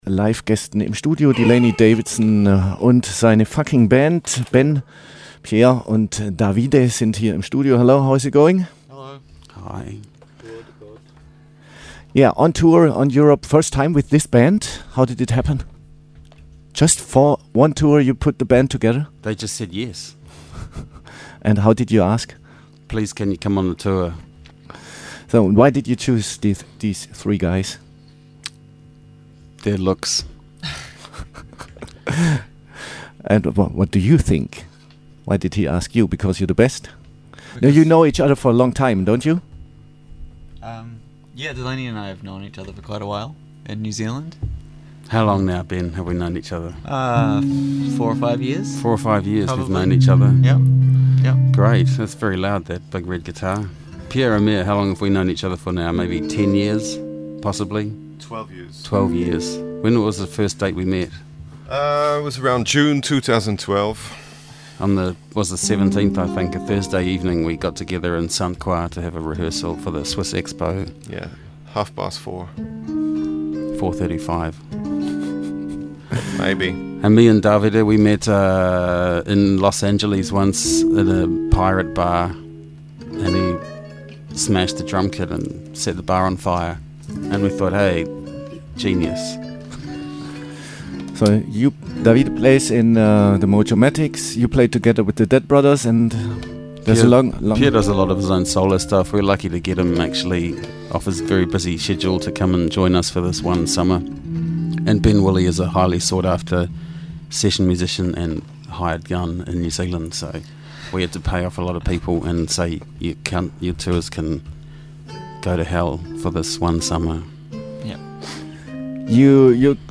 Stattdessen hat der Meister quasi die Seiten gewechselt, die Gesprächsführung an sich genommen und seiner Band kurzerhand lieber selbst die Fragen gestellt.
Spass haben sie trotz allem jede Menge zusammen und gute Musik machen sie sowieso.
Interview